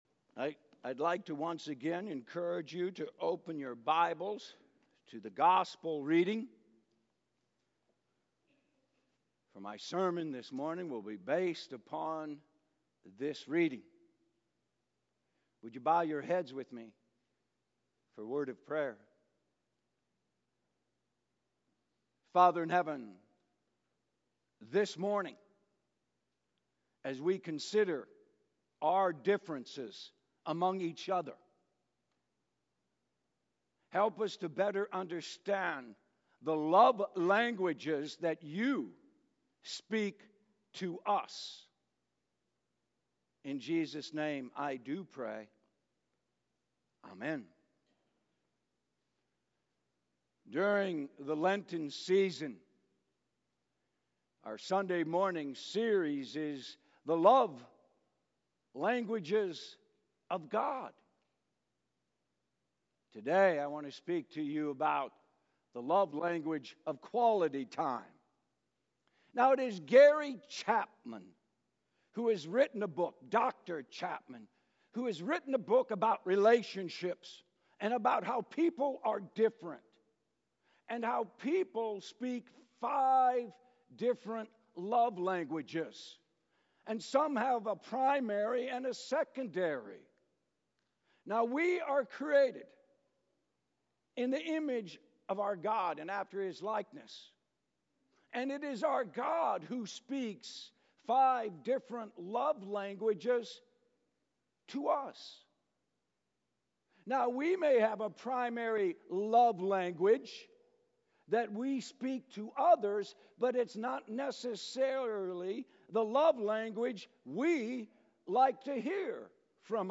A message from the series "HIStory in 2024." Today we see that because a king could not sleep one night, he had the chronicles of his kingdom read aloud to him.